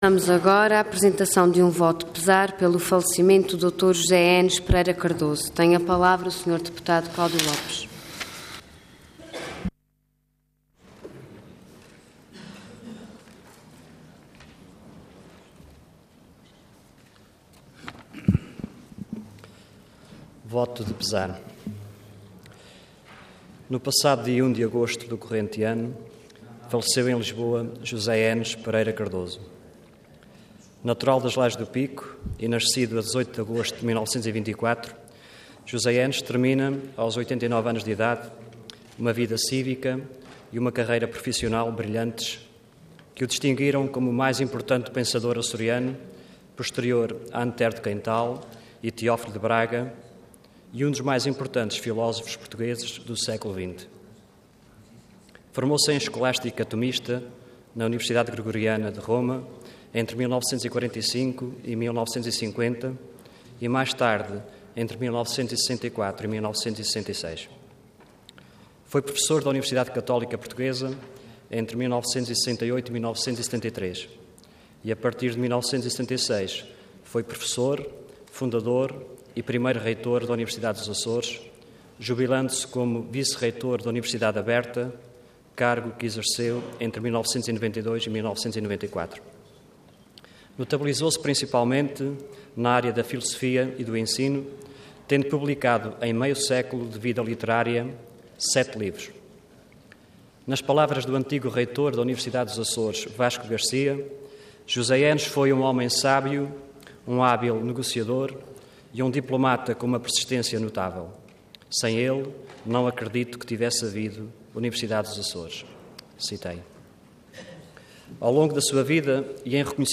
Intervenção Voto de Pesar Orador Cláudio Lopes Cargo Deputado Entidade PSD